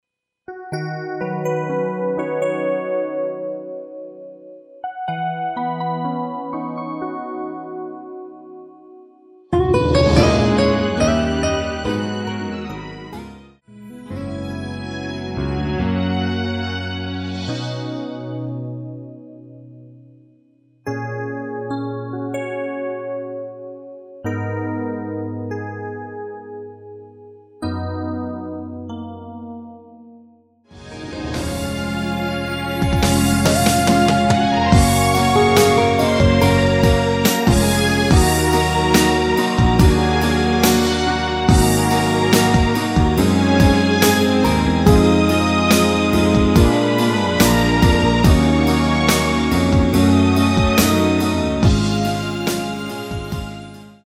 MR입니다.
듀엣곡 키 B
원곡의 보컬 목소리를 MR에 약하게 넣어서 제작한 MR이며